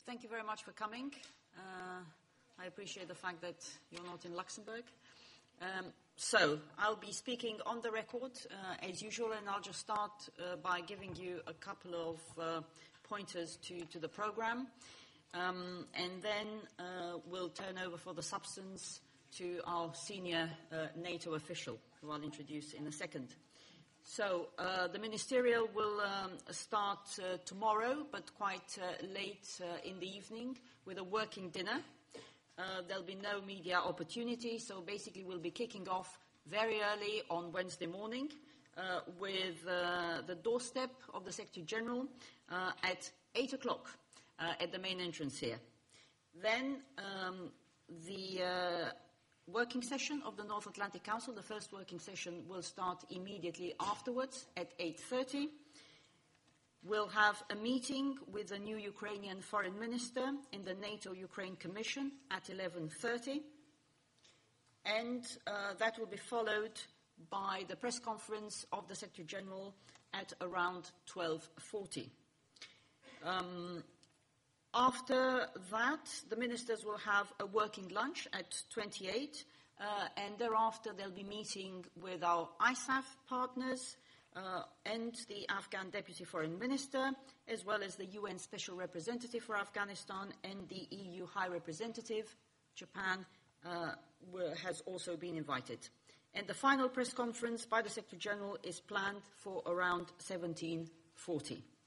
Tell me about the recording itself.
at the pre-ministerial briefing